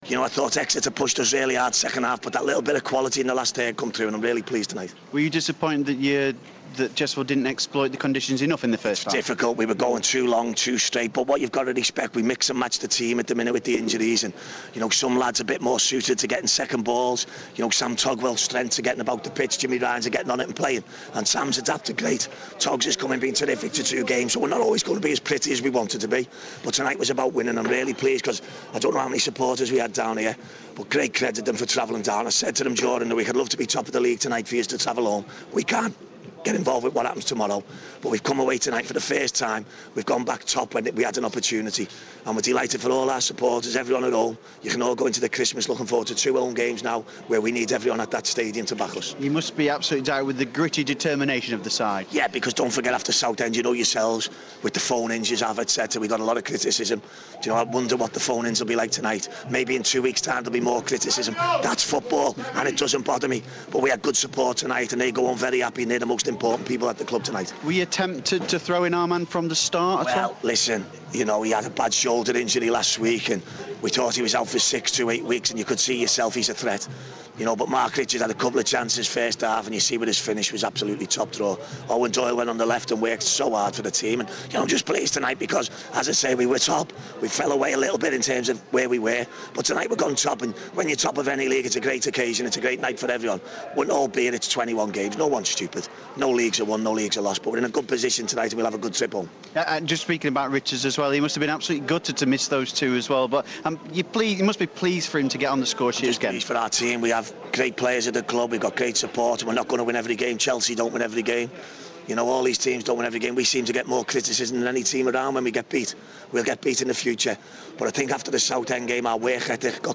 post-game interview after a 2-0 win at Exeter